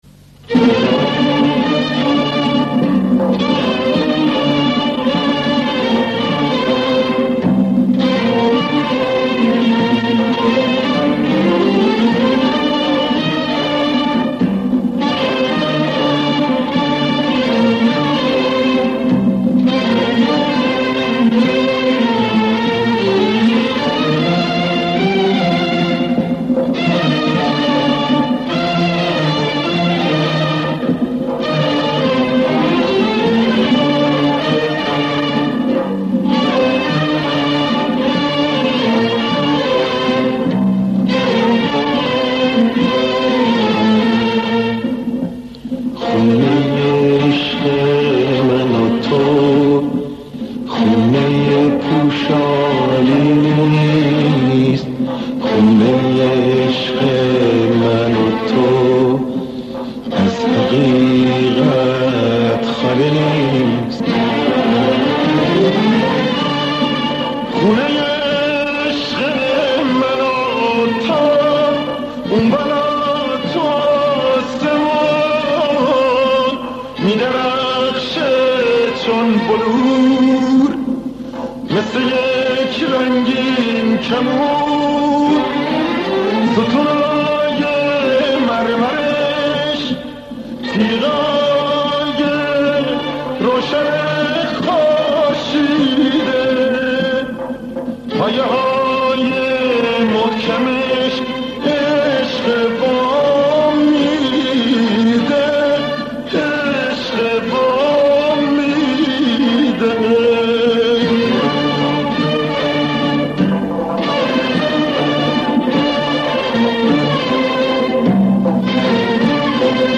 ژانر: پاپ
✅ آثار عاشقانه و احساسی با صدای گرم و پرقدرت
✅ تصنیف‌ها و آوازهایی با تنظیم‌های کلاسیک و شنیدنی